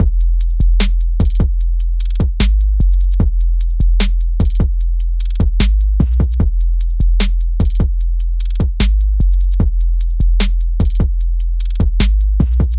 However, when it comes to the sounds like sub-basses primarily based on sine waves, situations change.
As mentioned earlier, sine waves don’t have any overtones, so such sounds may be completely inaudible depending on the environment, posing a risk of significantly altering the impression of the song.
P-timbre-subbass.mp3